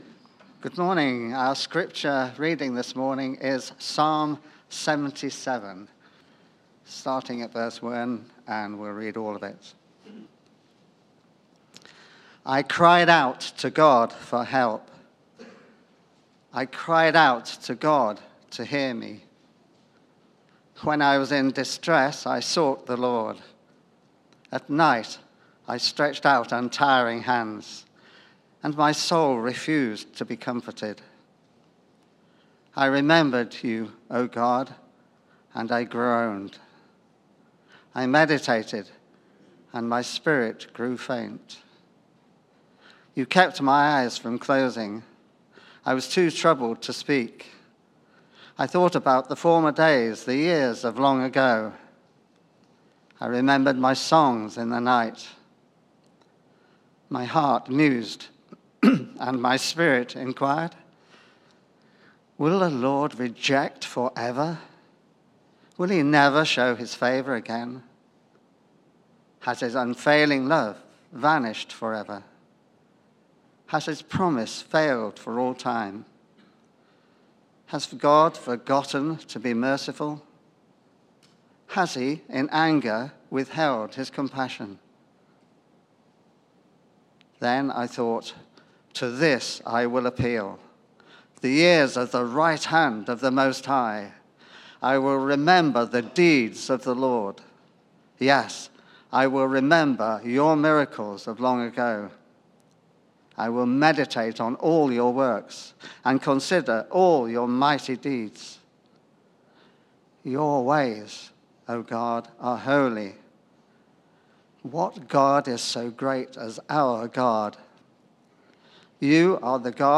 Media for Sunday Service
Theme: Where is God in times of trouble Sermon